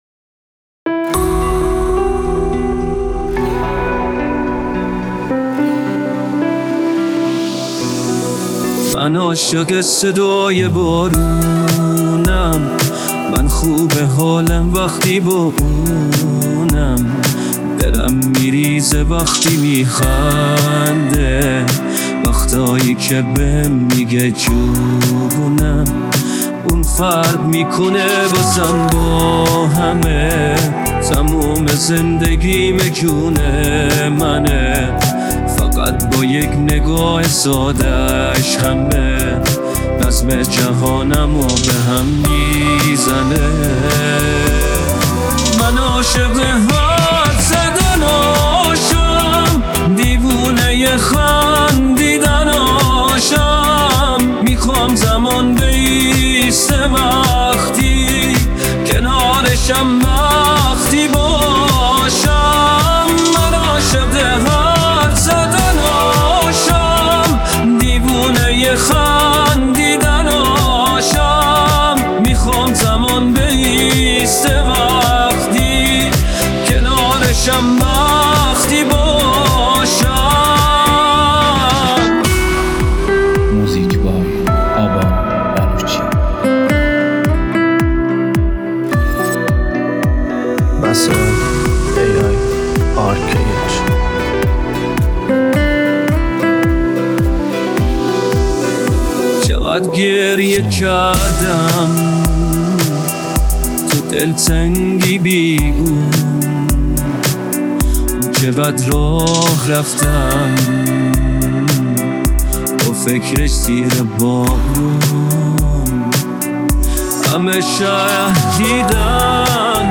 دانلود آهنگ پاپ ایرانی دانلود آهنگ های هوش مصنوعی